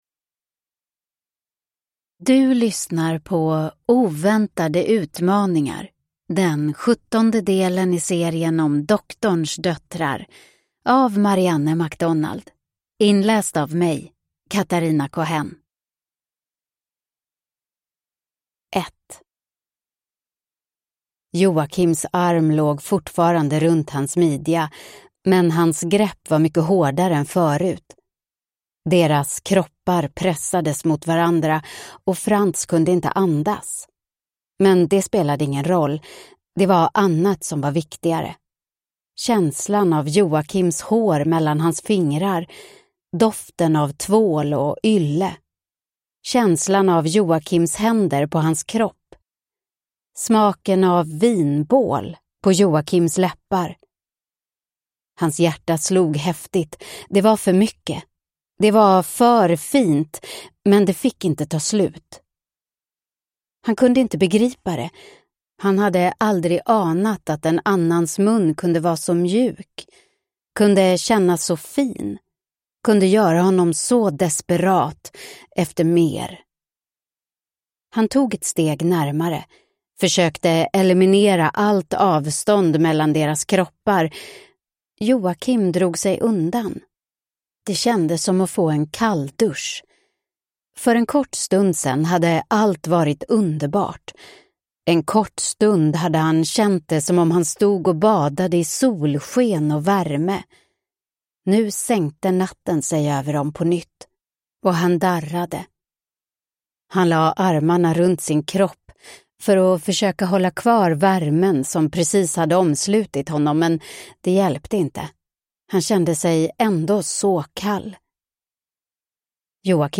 Oväntade utmaningar (ljudbok) av Marianne MacDonald